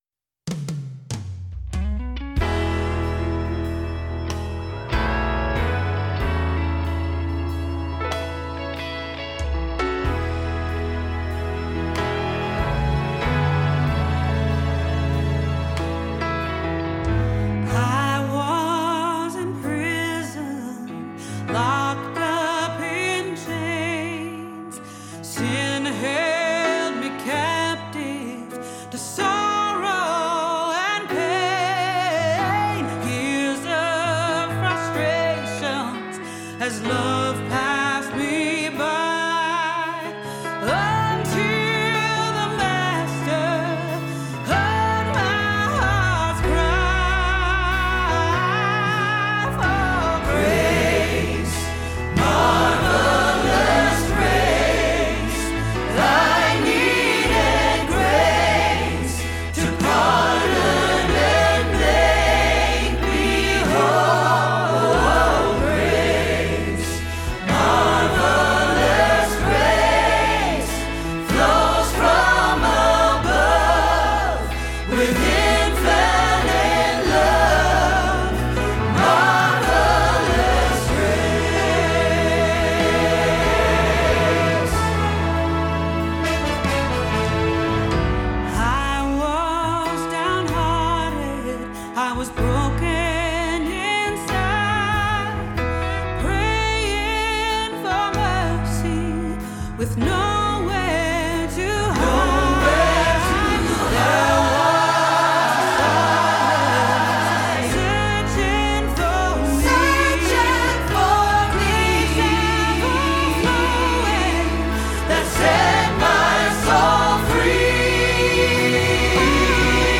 Grace with Grace Greater Than Our Sin – Alto – Hilltop Choir
02-Grace-with-Grace-Greater-Than-Our-Sin-Alto-Rehearsal-Track.mp3